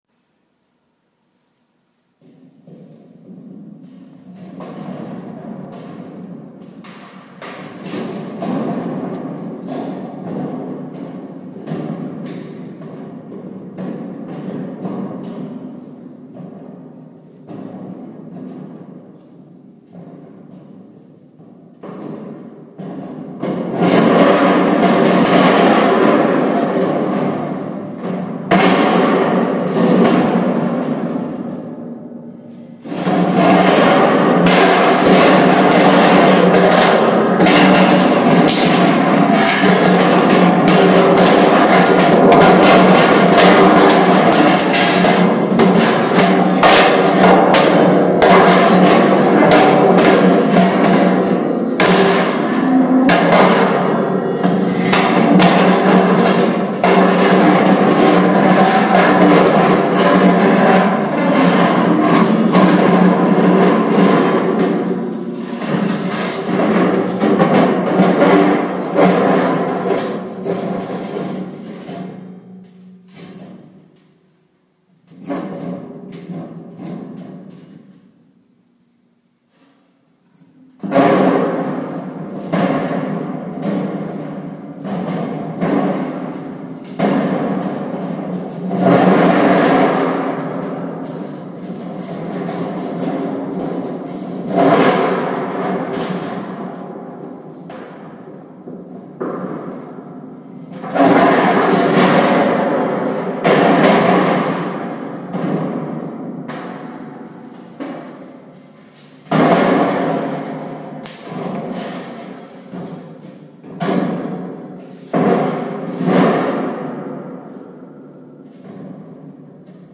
L’opera nasce a seguito dei numerosi attentati degli estremisti islamici in tutto il mondo e in particolare modo nelle vicine città di Parigi e Bruxelles. In sottofondo il suono duro e sofferente della lamiera trascinata a terra è metafora degli spari e delle urla umane in preda al terrore.